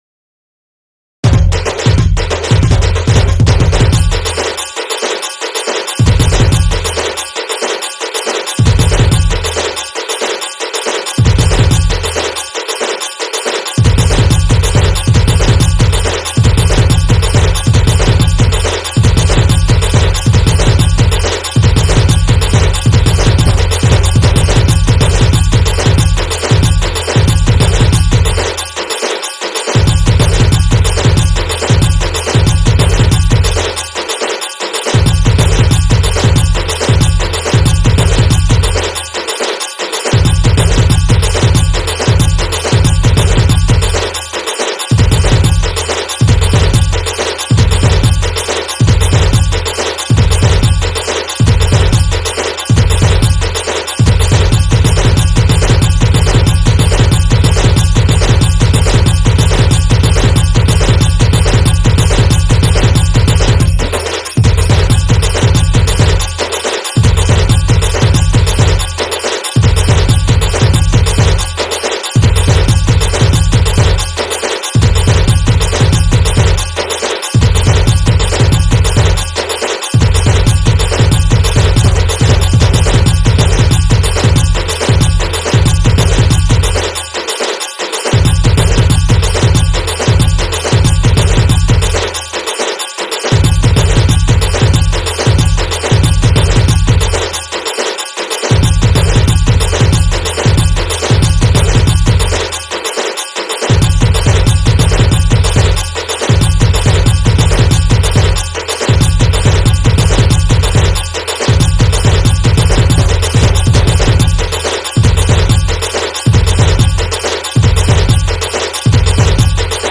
Instrumental Music And Rhythm Track Songs Download